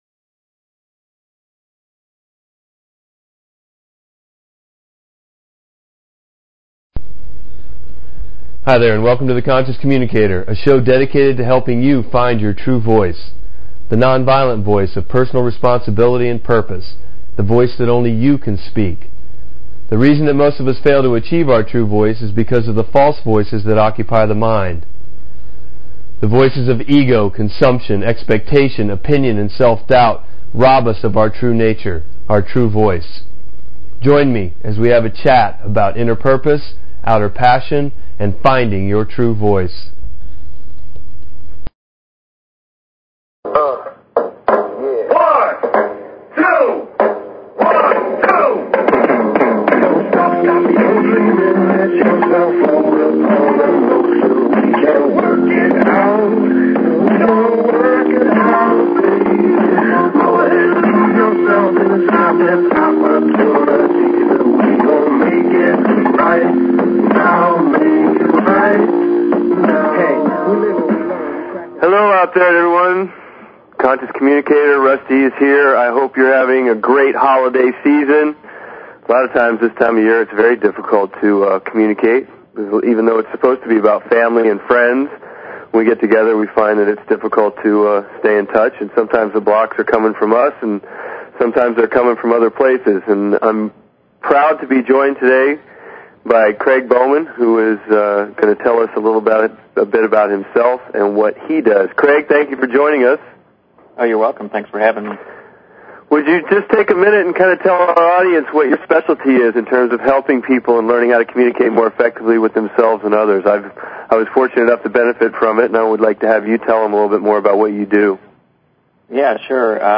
Talk Show Episode, Audio Podcast, The_Conscious_Communicator and Courtesy of BBS Radio on , show guests , about , categorized as